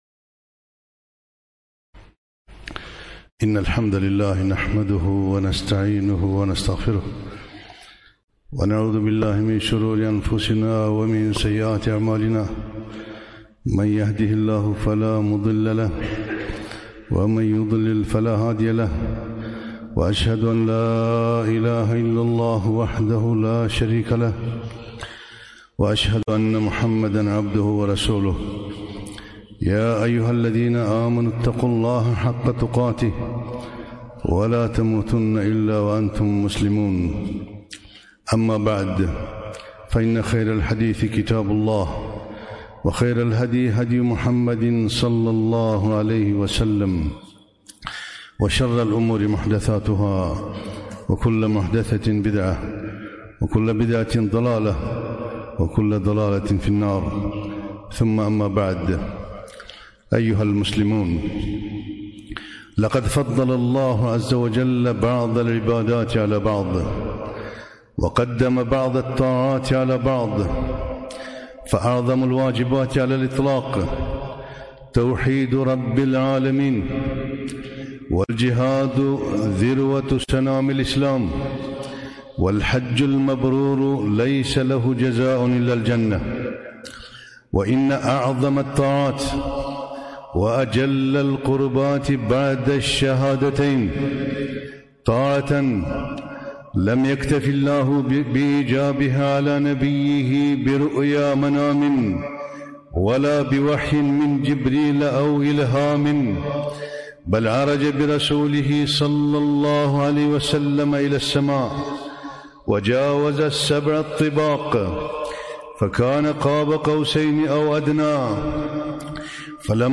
خطبة - الخشوع في الصلاة